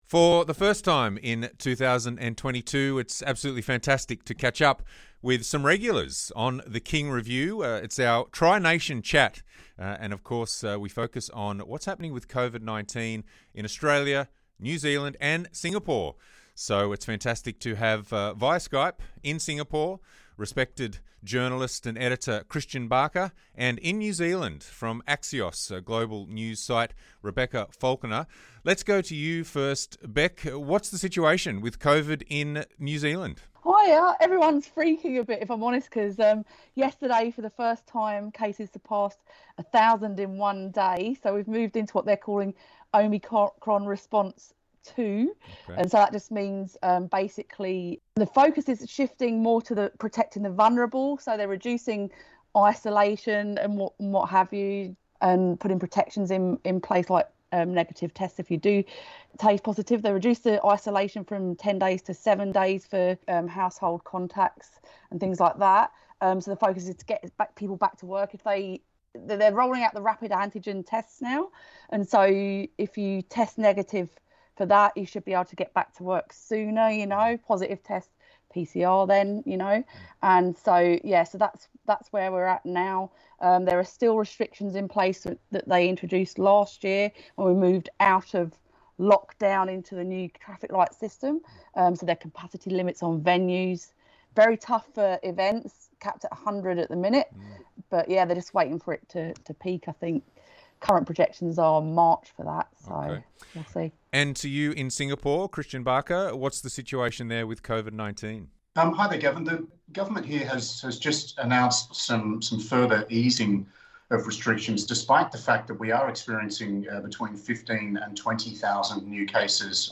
crosses to journalists in New Zealand and Singapore for a tri-nation chat about the status of COVID-19 restrictions in the three countries.